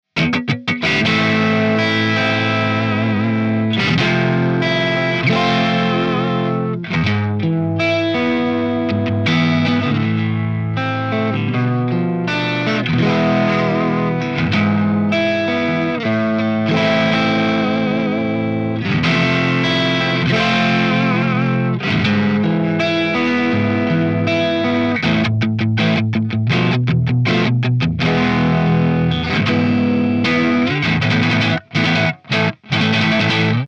The M1 Epona pack includes captures ranging from clean to full metal hi gain and everything in between plus my personal YouTube IR that I use in my demos are also included.
Chords
RAW AUDIO CLIPS ONLY, NO POST-PROCESSING EFFECTS